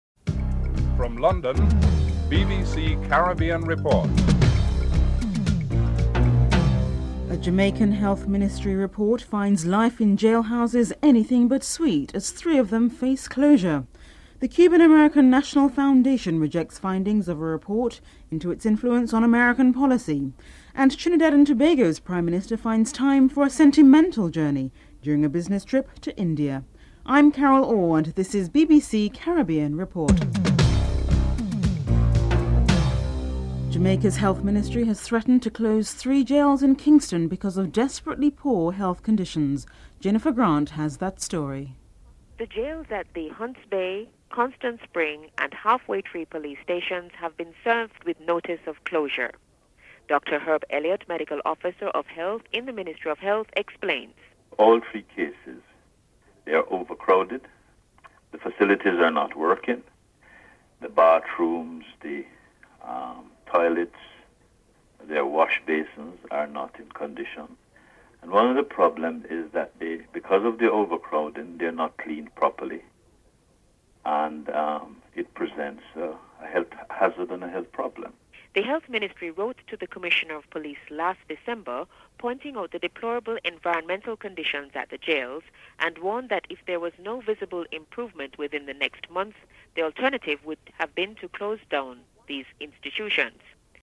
7. Fourth test starts today - West Indies versus Australia. Brian Lara is interviewed (10:33-14:11)